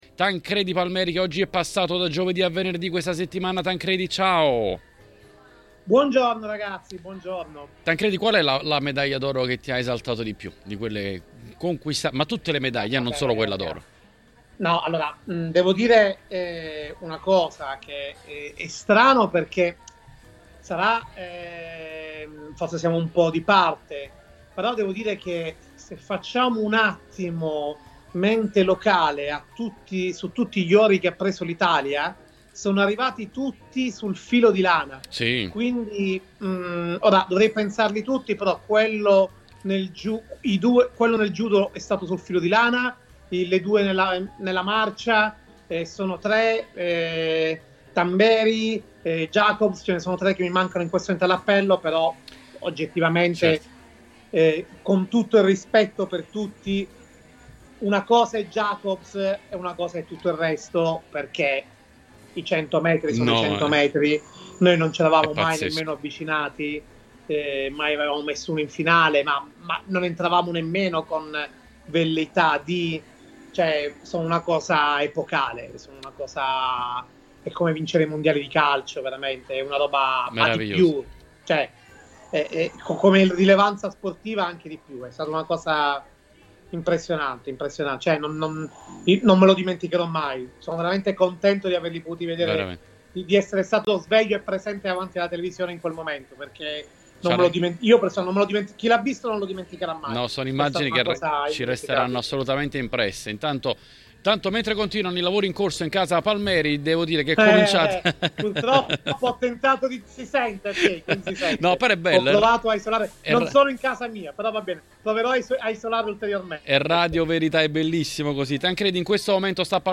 Editoriale